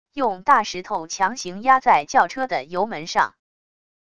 用大石头强行压在轿车的油门上wav音频